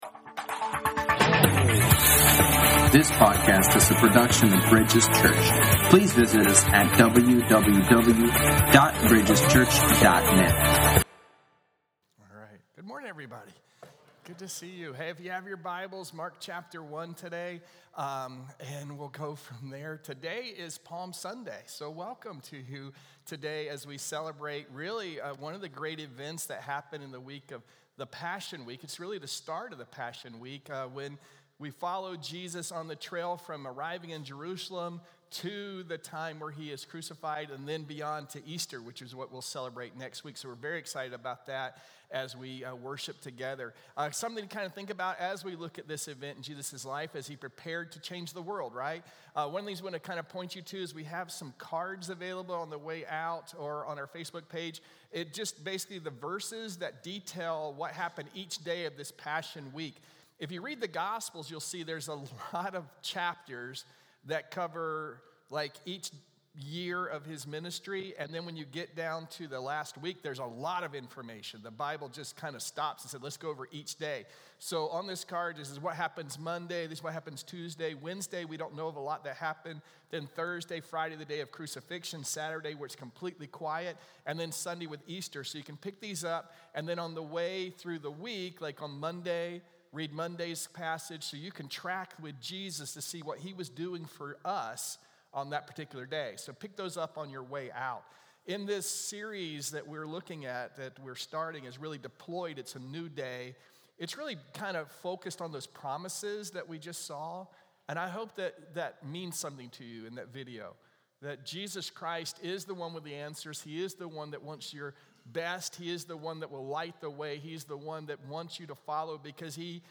Sermons | Bridges Church